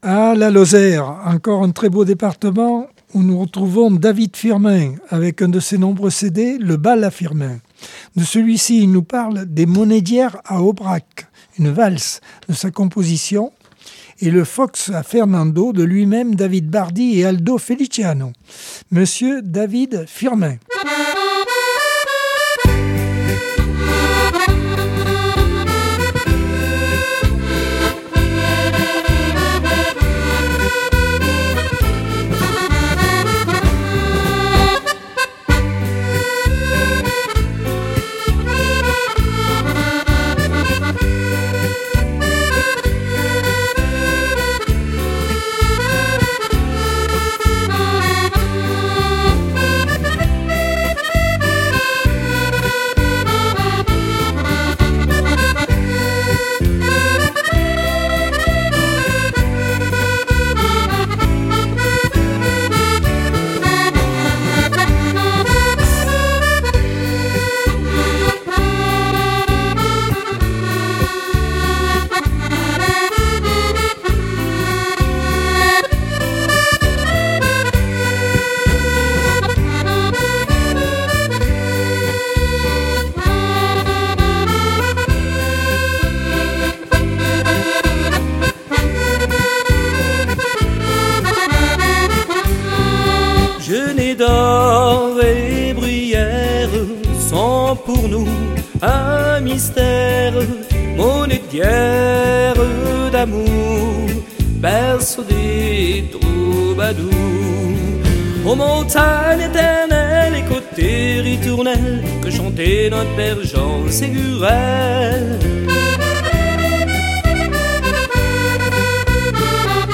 Accordeon 2024 sem 51 bloc 4 - Radio ACX